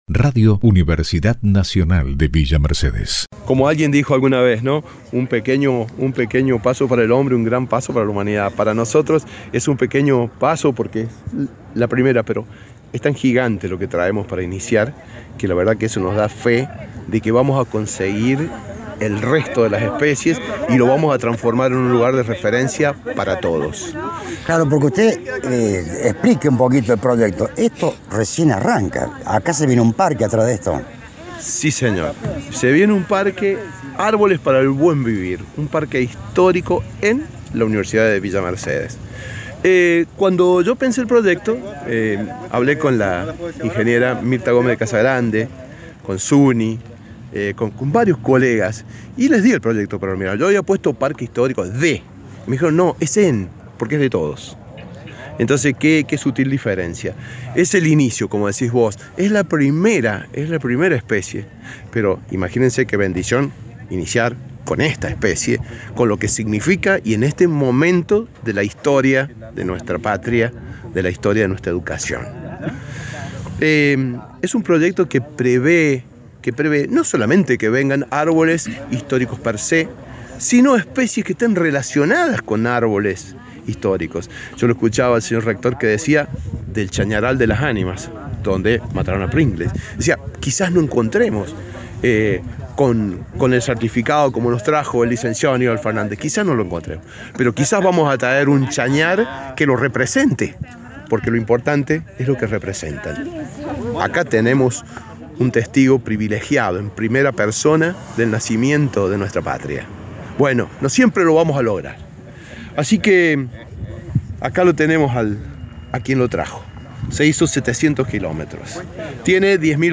Al término del acto, Prensa Institucional recabó la opinión del Rector Marcelo Sosa